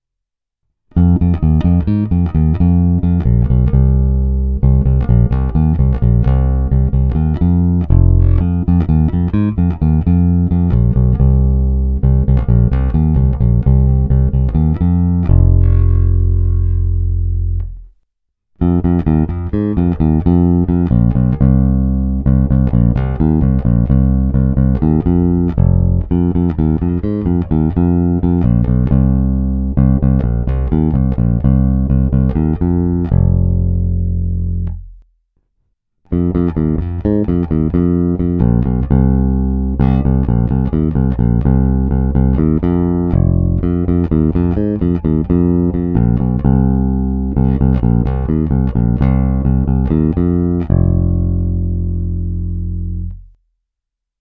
Zvuk je tlustý, zvonivý vrčák s okamžitým nástupem pevného tónu.
Není-li uvedeno jinak, následující nahrávky jsou provedeny rovnou do zvukovky a dále kromě normalizace ponechány bez úprav.
Tónová clona vždy plně otevřená.